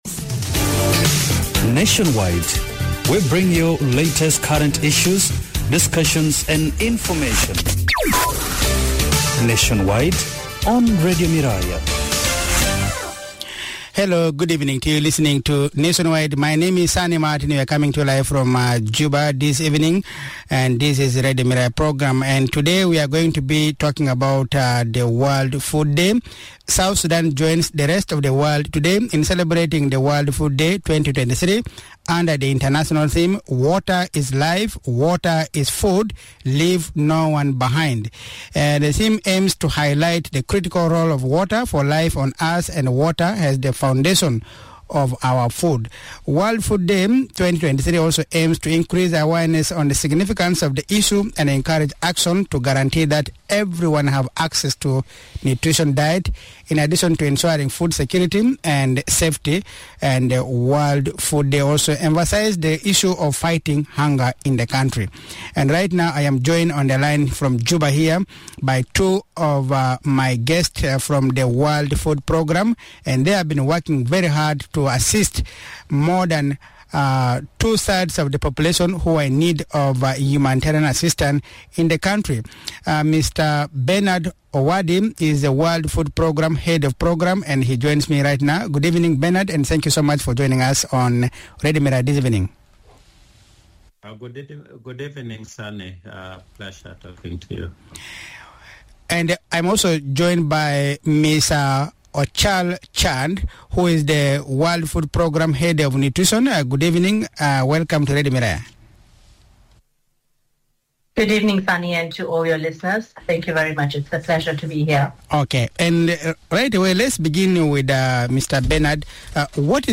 This discussion focused on the significance of the day to a country like South Sudan where more than two-thirds of the population are in need of humanitarian assistance.